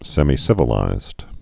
(sĕmē-sĭvə-līzd, sĕmī-)